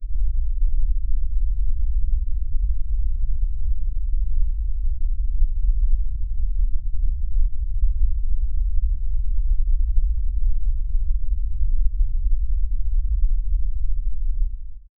dull deep sound of water slowly flowing low
dull-deep-sound-of-water-pobox2c2.wav